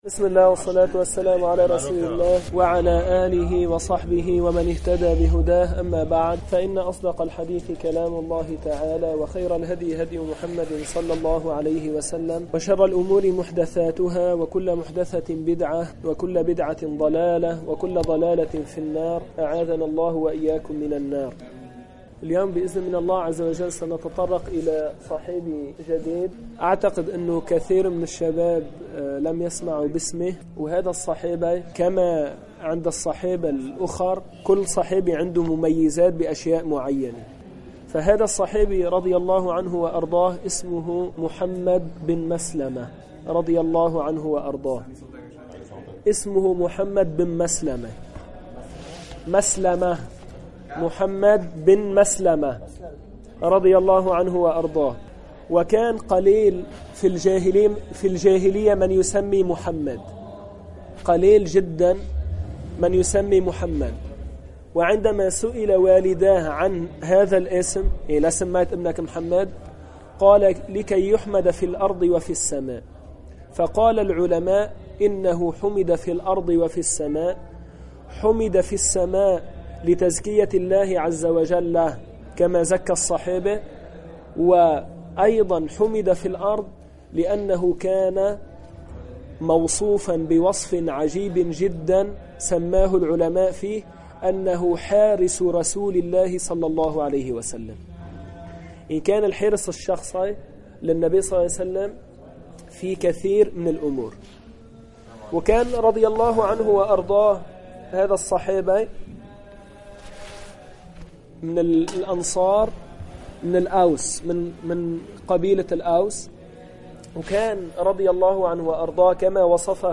من دروس مسجد القلمون الغربي الشرعية